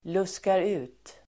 Ladda ner uttalet
Uttal: [²luskar'u:t]